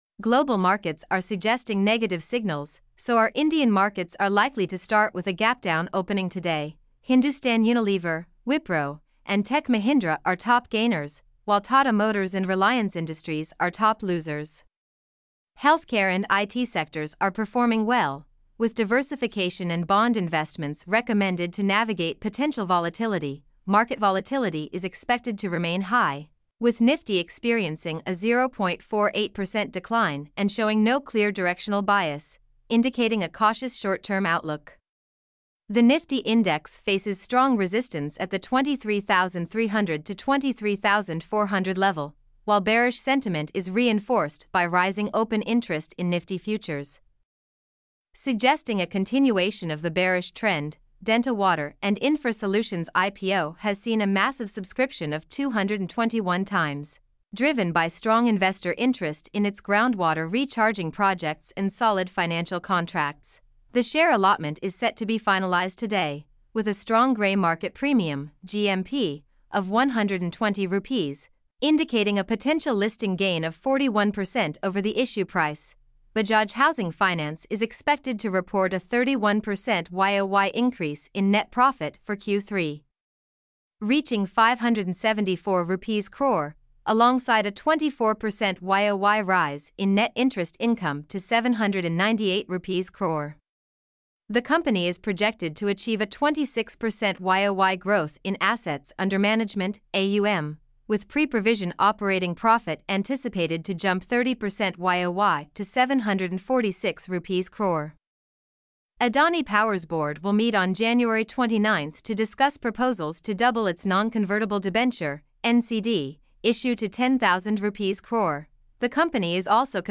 mp3-output-ttsfreedotcom-2-1.mp3